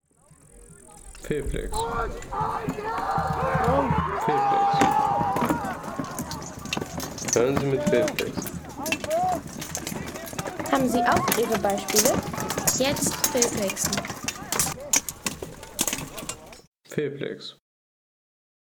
Mittelalterliche Schlacht
Mittelalterlicher Angriff – Fußsoldaten im Kampfgetümmel.